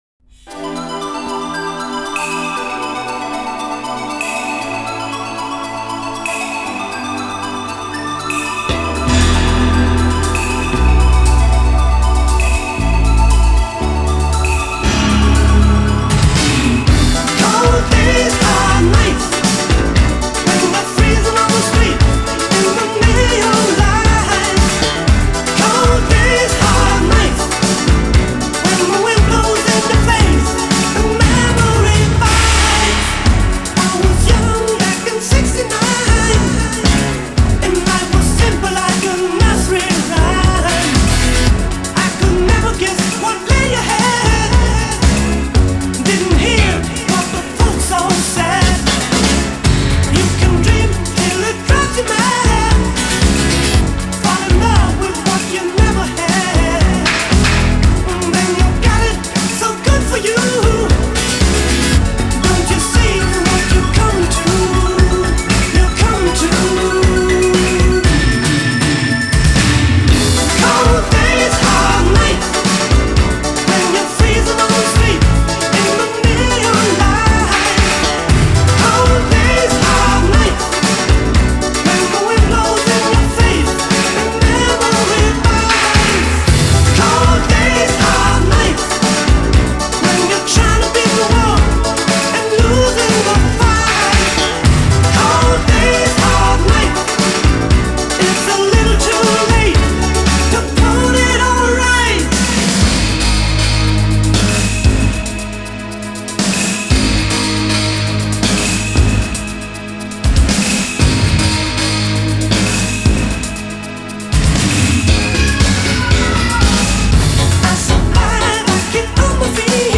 Жанр: Pop/Synth-pop